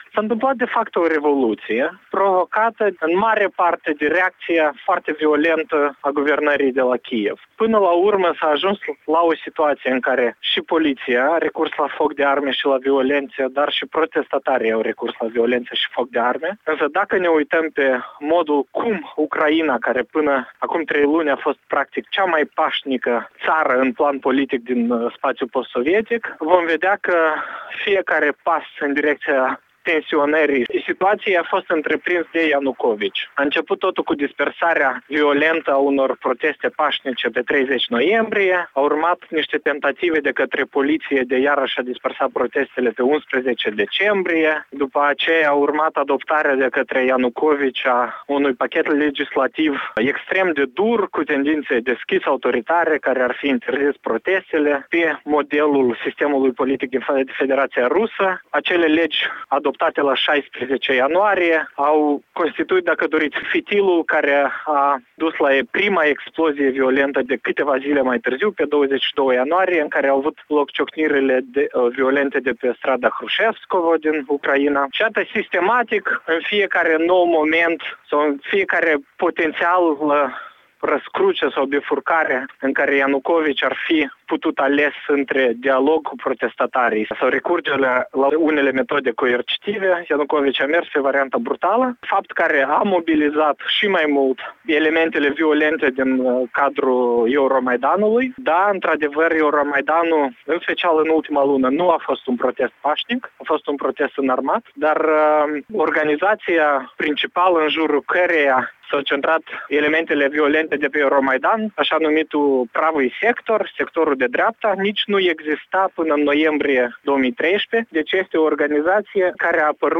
Ucraina, încotro? - Interviul integral acordat Europei Libere de analistul politic Nicu Popescu